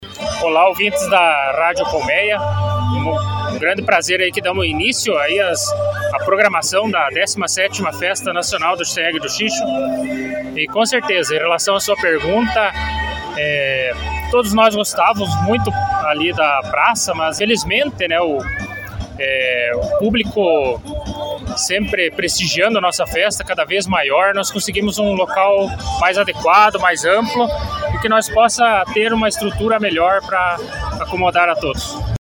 A solenidade de abertura ocorreu em frente ao Clube Apolo, reunindo autoridades, organizadores e a comunidade local.